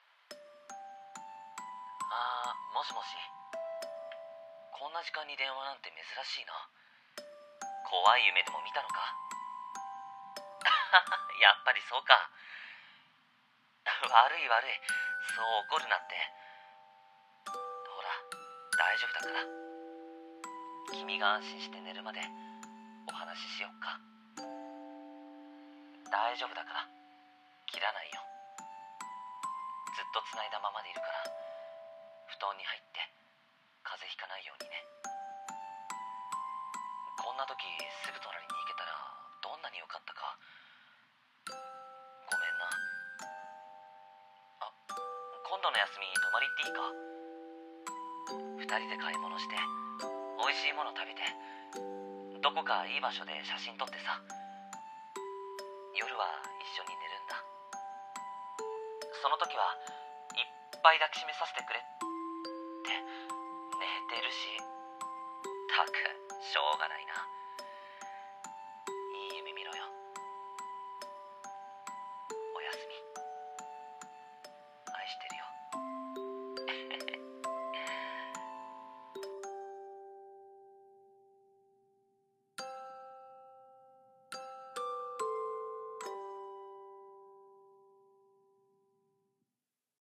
【一人声劇】